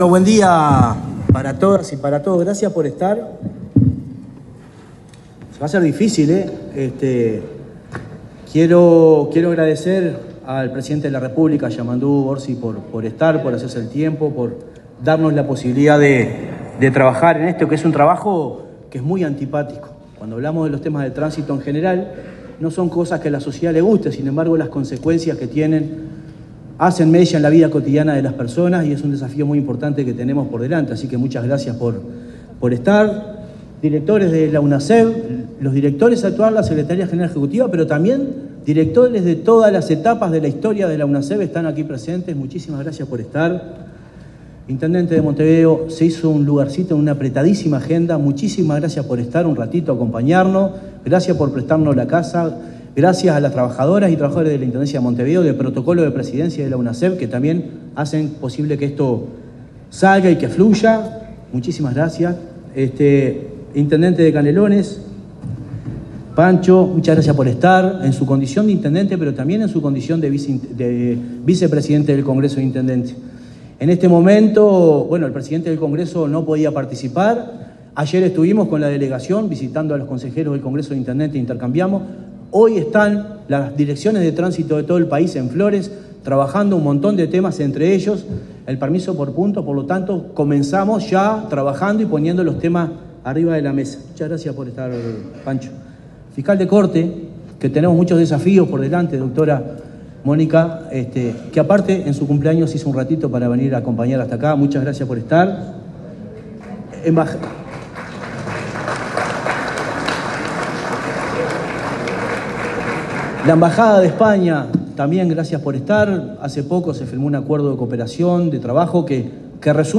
Palabras del presidente de la Unasev, Marcelo Metediera